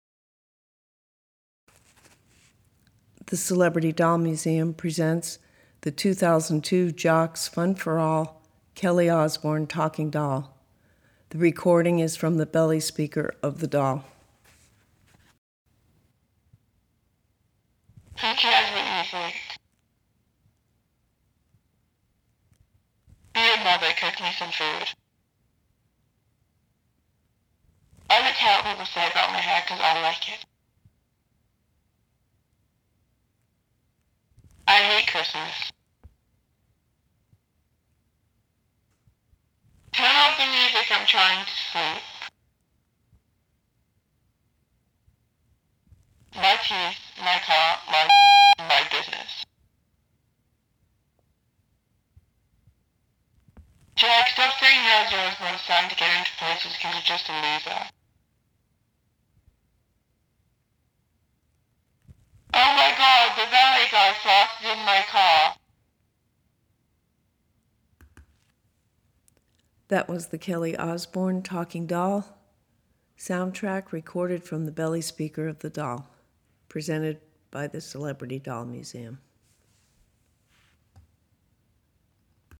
This was sold as a talking doll, but the doll which is over 10 years old has barely working batteries and the talk box just garbles.
The Kelly Osbourne talking doll has 8 separate somewhat garbled expressions.  To hear a recording of the sound track recorded from the doll’s belly speaker, click here:
CDMKellyOsbournetalkingdoll.mp3